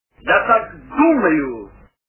» Звуки » Люди фразы » Мимино - Я так думаю
При прослушивании Мимино - Я так думаю качество понижено и присутствуют гудки.